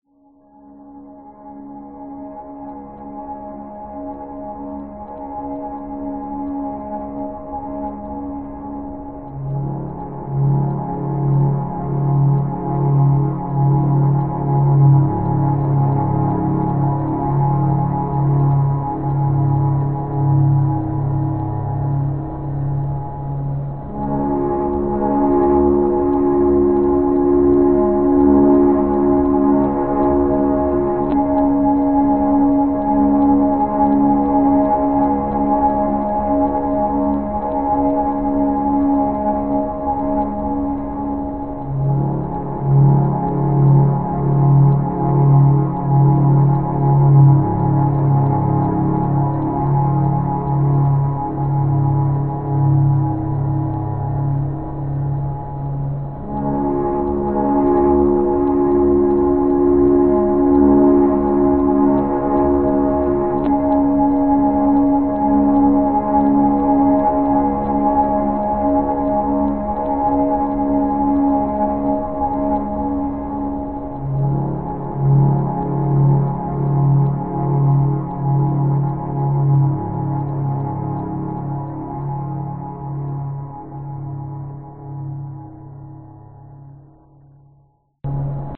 描述：氛围04。属于合成无人机和气氛的集合。
标签： 环境 无人机 大气
声道立体声